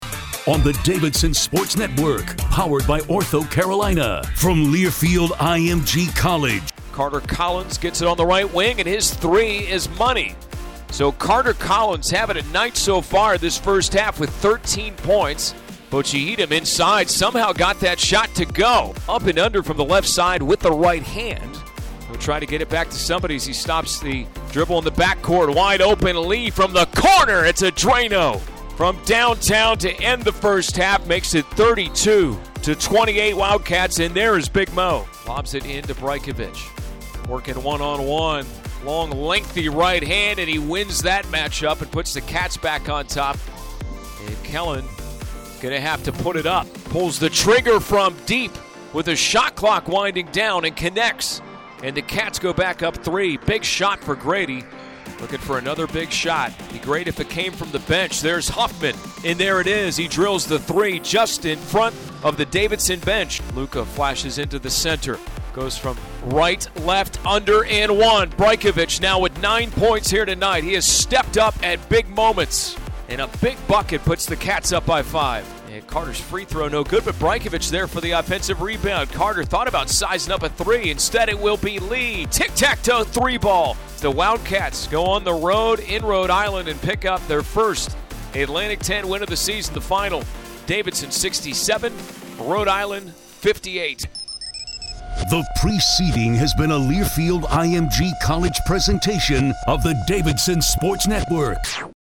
Radio Highlights
Davidson at URI Highlights.mp3